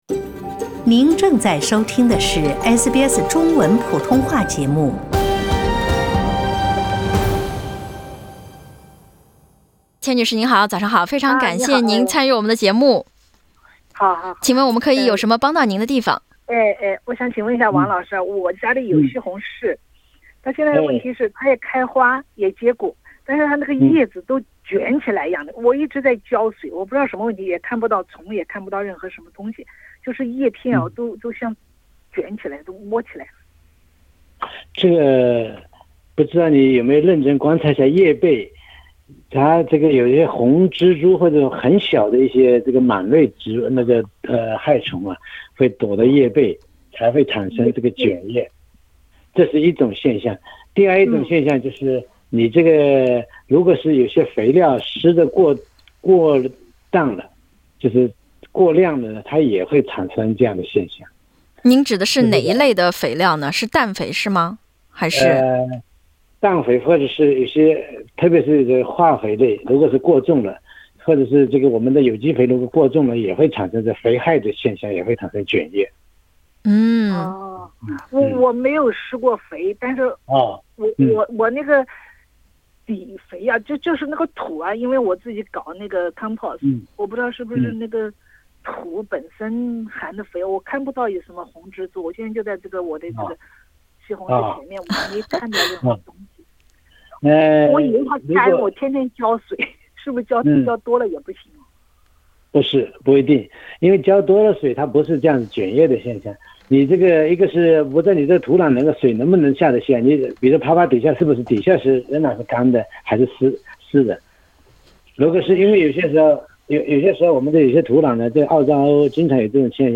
2019年最后一期热线《我的花园，我的菜地》，听众发力提问，嘉宾详细作答，病虫害、施肥问题一网打尽。